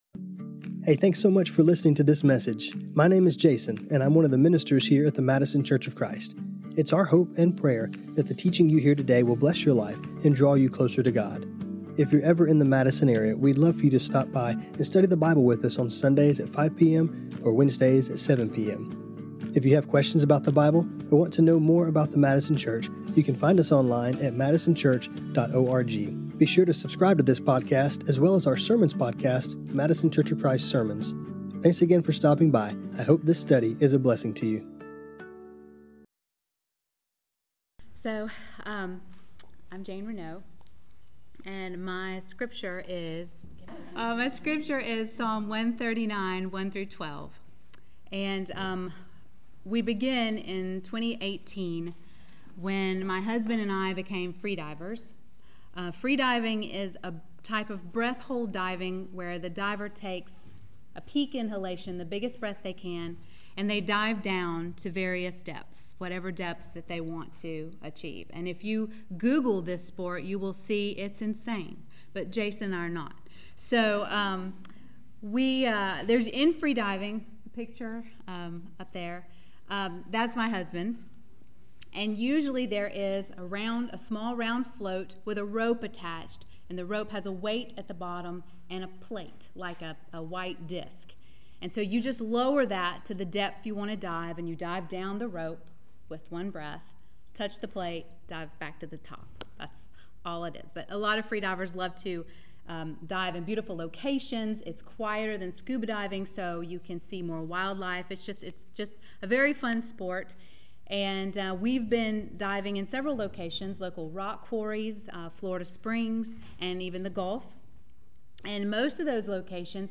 When we go through difficult times and even wonderful times, God has given us something that is constant, His word. In this class, we will hear from some of our own sisters as they share the scriptures that have carried them through different life circumstances both good and bad.